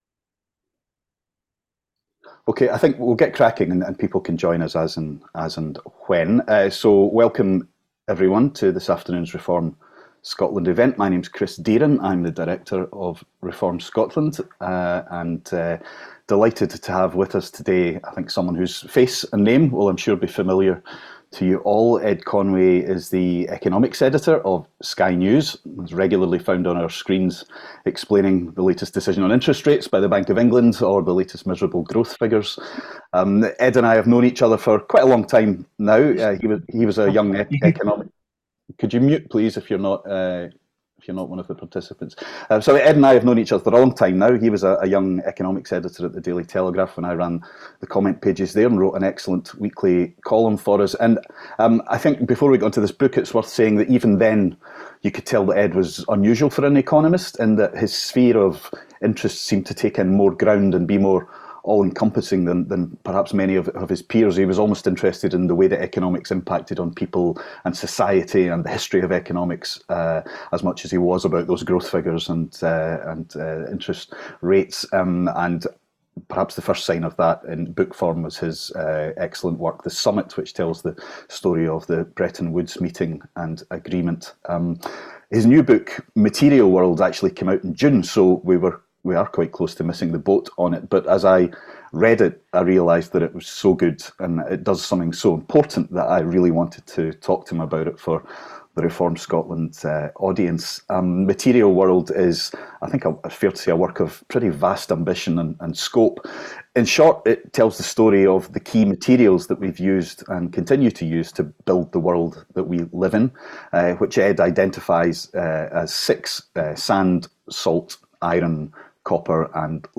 Reform Scotland was delighted to host a discussion with Ed Conway, Economics Editor of Sky News.